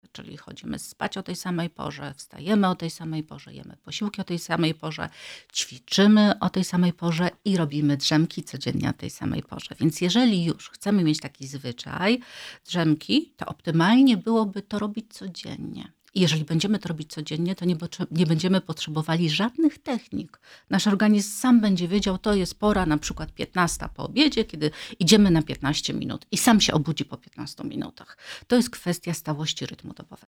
W studiu Radia Rodzina